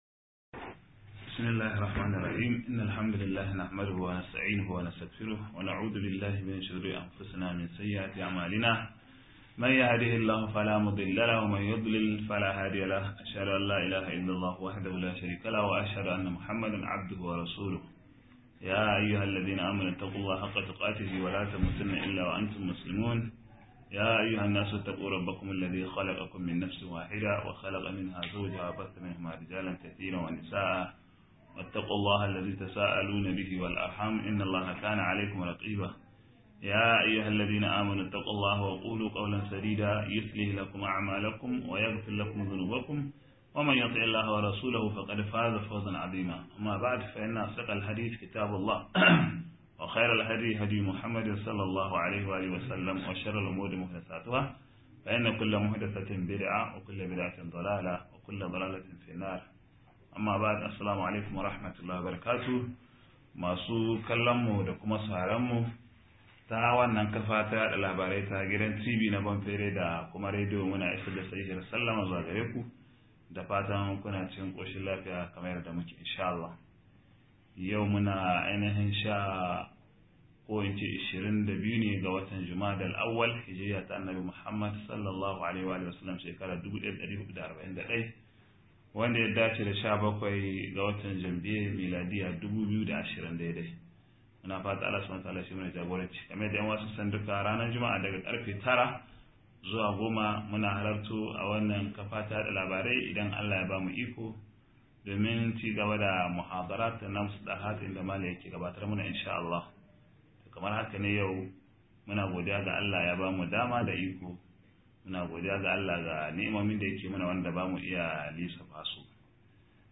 48-serving - MUHADARA